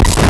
Player_Glitch [72].wav